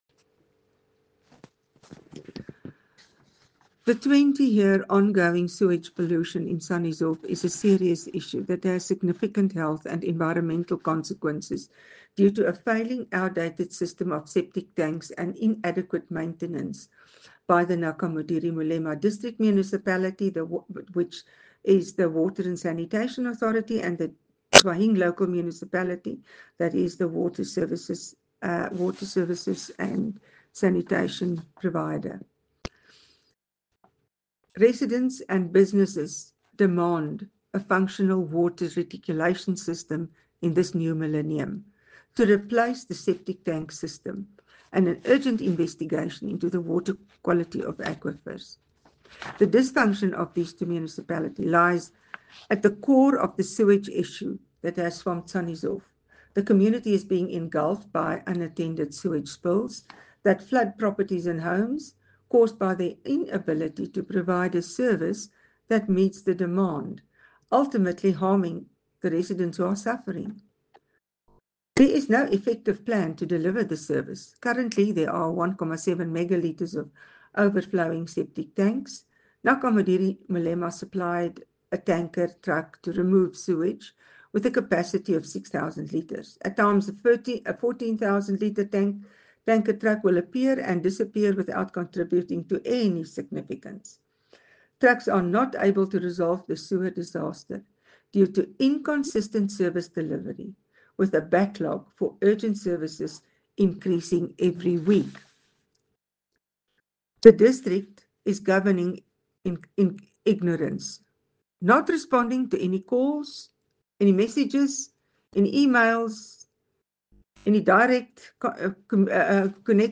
Issued by Carin Visser – DA Councillor, Tswaing Local Municipality
Note to Broadcasters: Please find attached soundbites in